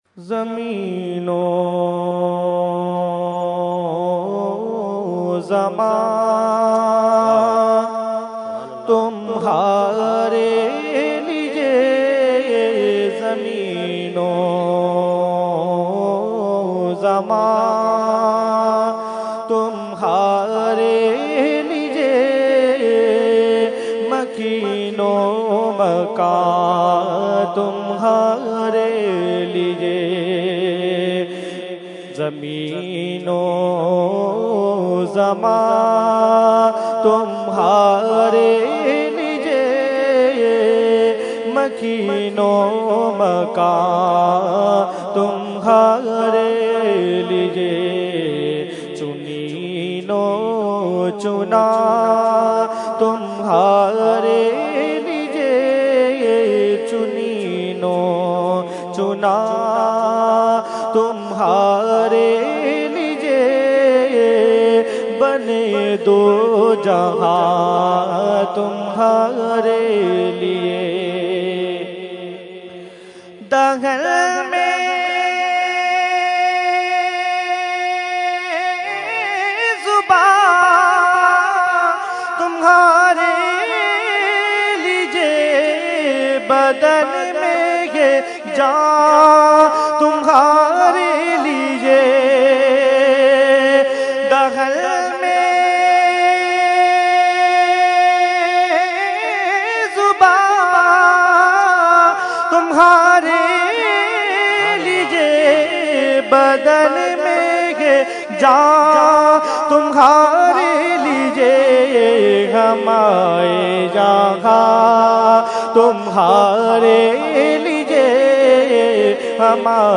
Category : Naat | Language : UrduEvent : Urs Ashraful Mashaikh 2015